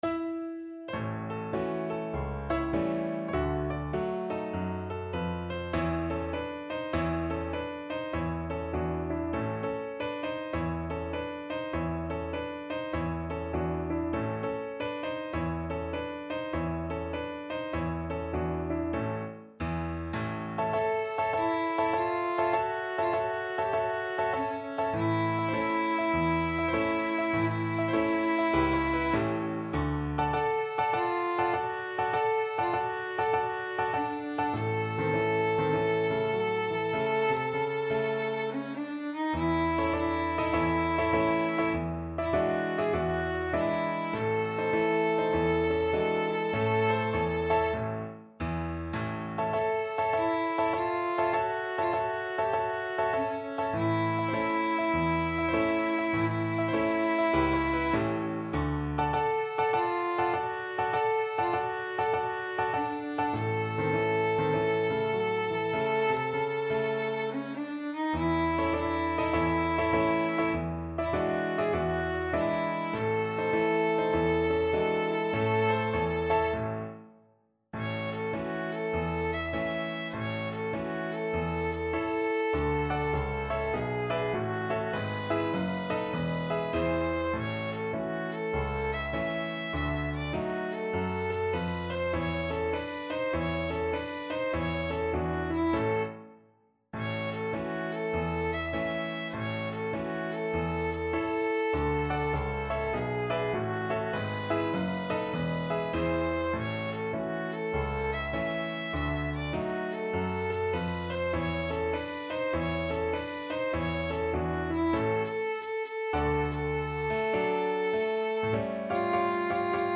4/4 (View more 4/4 Music)
Jazz (View more Jazz Violin Music)